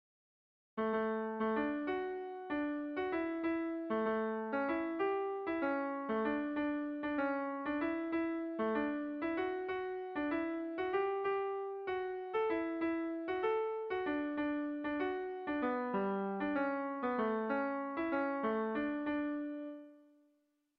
Bertso melodies - View details   To know more about this section
Kontakizunezkoa
Seikoa, handiaren moldekoa, 4 puntuz (hg) / Lau puntukoa, handiaren moldekoa (ip)
ABDE